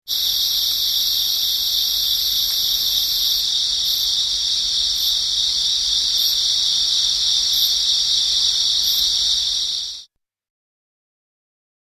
虫達（188KB）